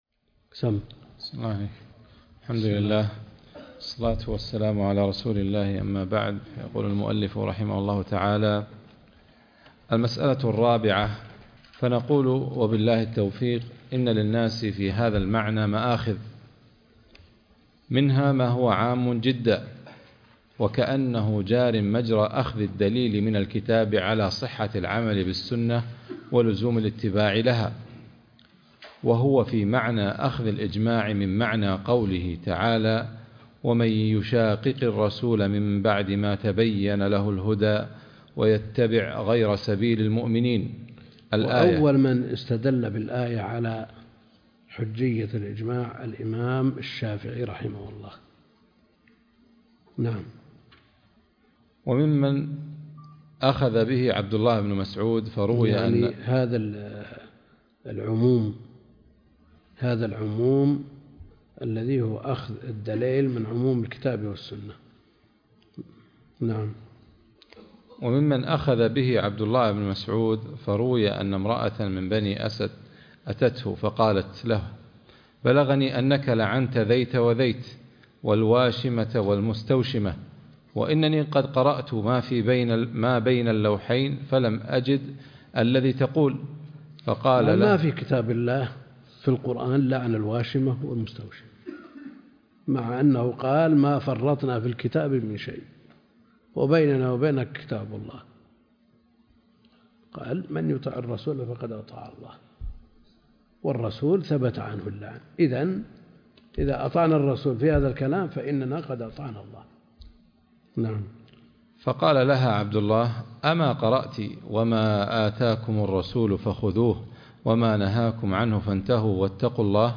الدرس ( 134) التعليق على الموافقات - الدكتور عبد الكريم الخضير